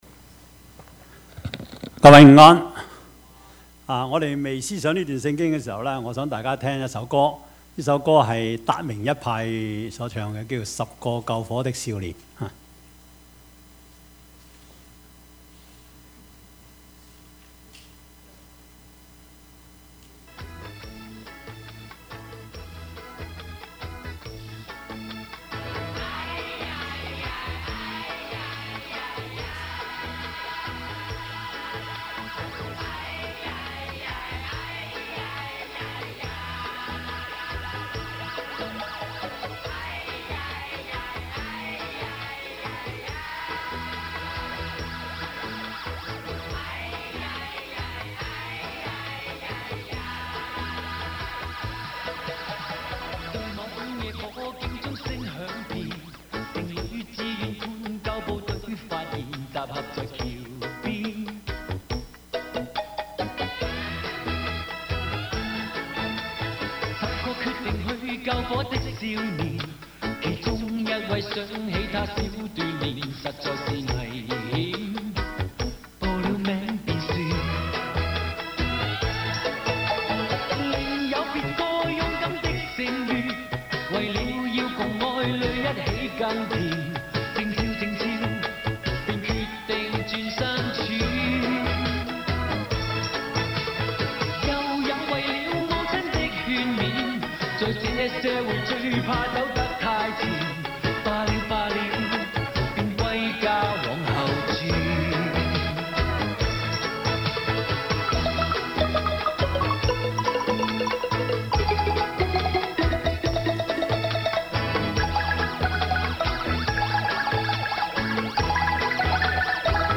Service Type: 主日崇拜
Topics: 主日證道 « 挪亞–異象人生 最大的是愛 »